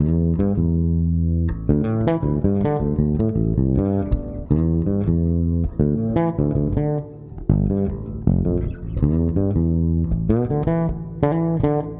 低音融合5
描述：适用于许多流派的无品类爵士贝斯的旋律
Tag: 80 bpm Fusion Loops Bass Guitar Loops 2.02 MB wav Key : E